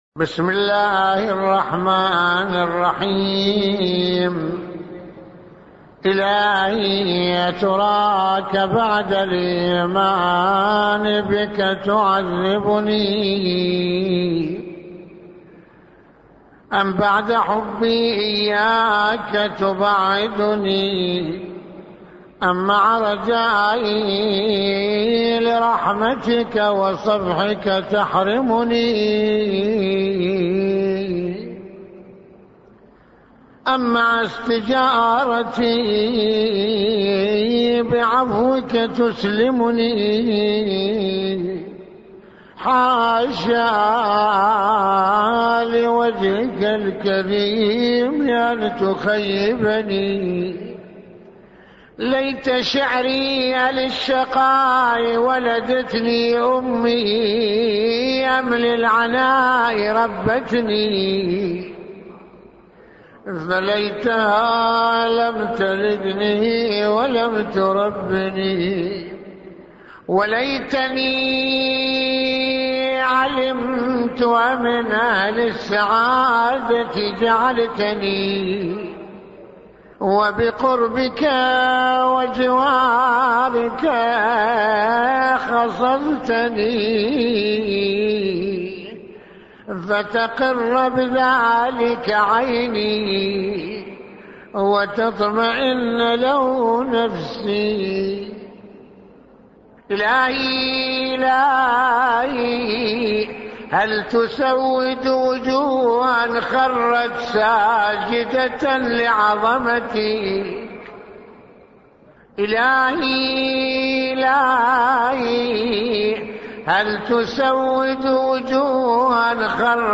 - استمع للدعاء بصوت سماحته